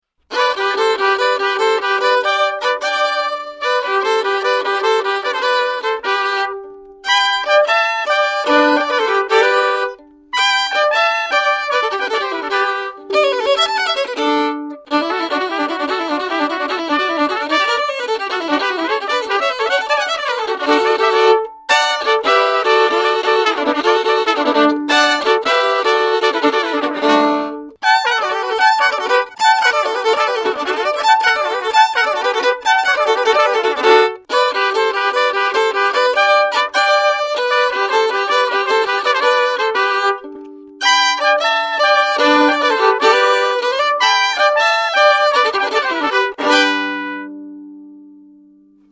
Žánr: Bluegrass.